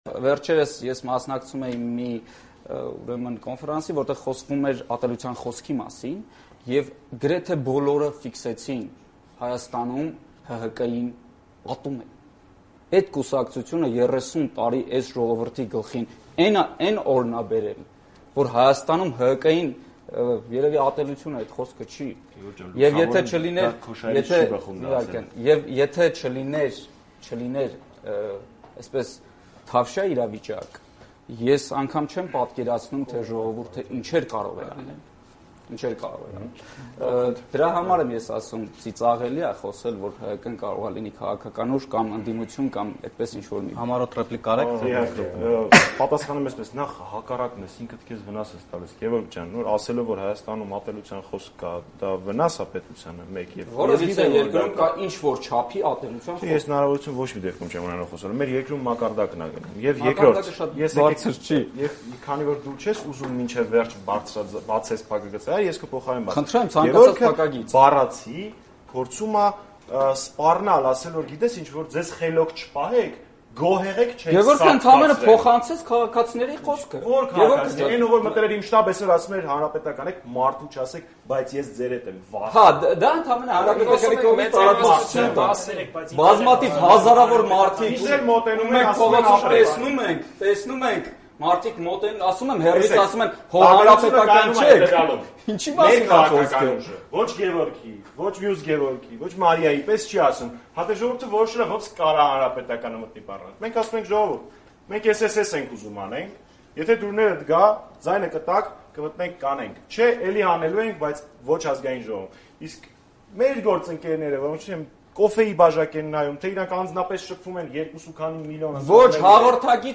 Նախընտրական բանավեճ